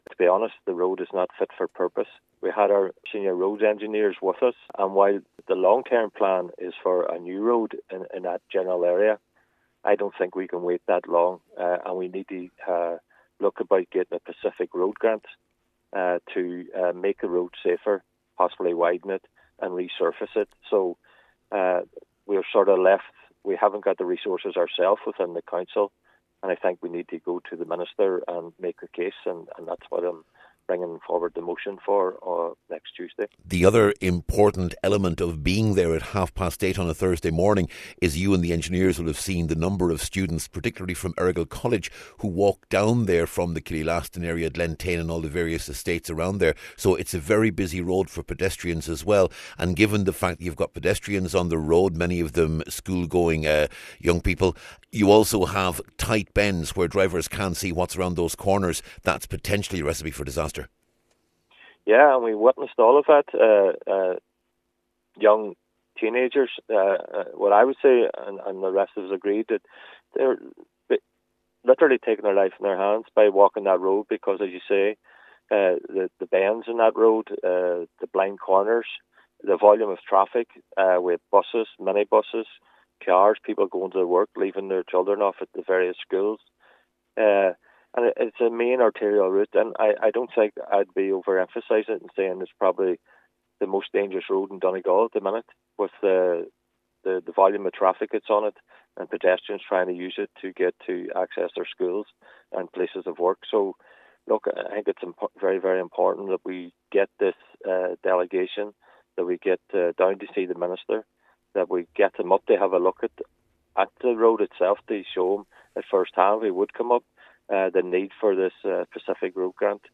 Cllr McMonagle says what they saw reinforced his belief that this is one of the most dangerous roads in Donegal………